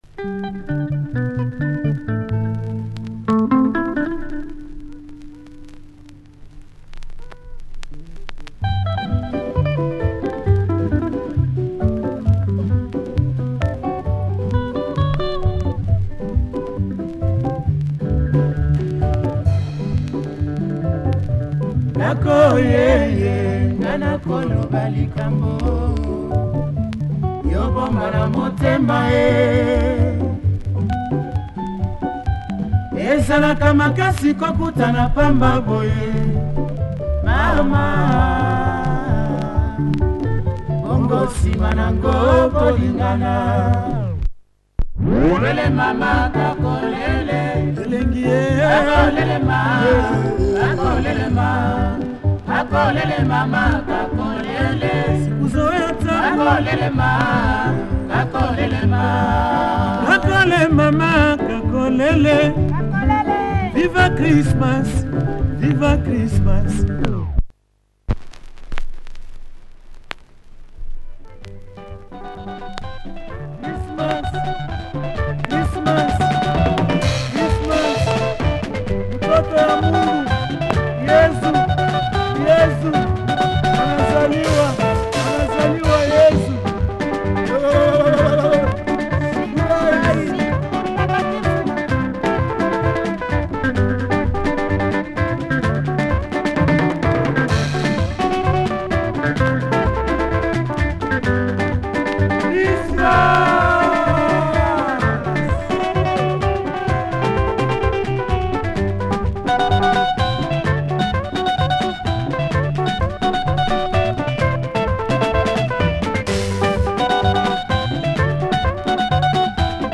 Congolese XMAS tune